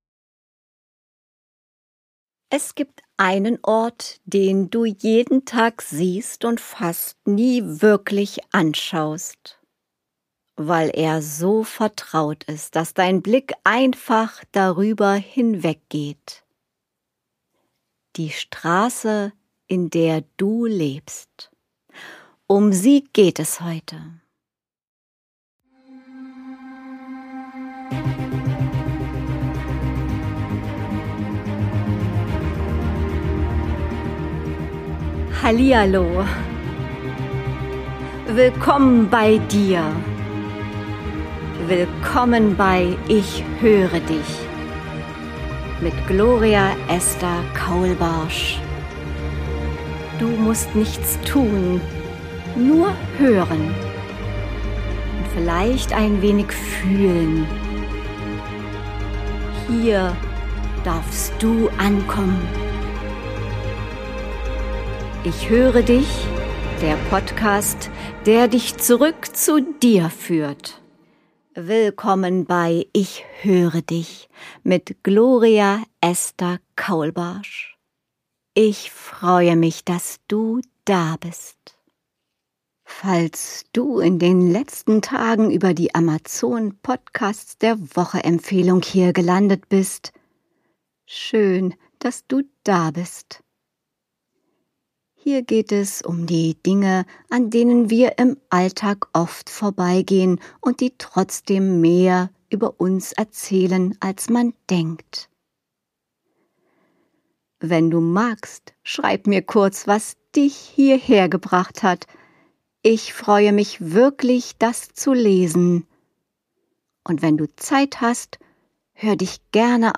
Aufgenommen im Greve Studio Berlin.